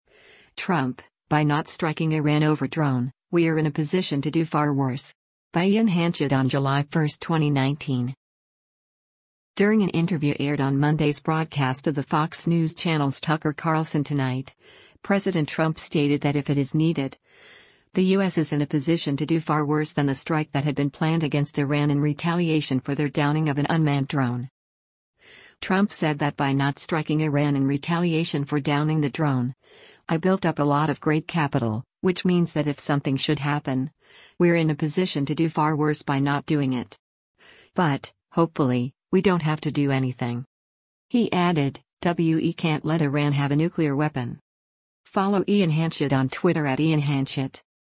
During an interview aired on Monday’s broadcast of the Fox News Channel’s “Tucker Carlson Tonight,” President Trump stated that if it is needed, the U.S. is in “a position to do far worse” than the strike that had been planned against Iran in retaliation for their downing of an unmanned drone.